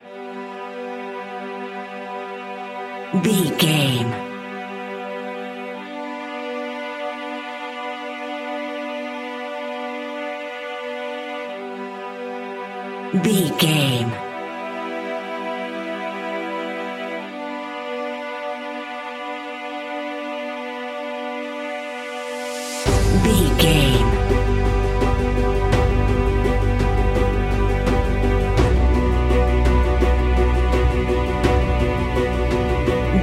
Epic / Action
Fast paced
In-crescendo
Uplifting
Aeolian/Minor
brass
percussion
synthesiser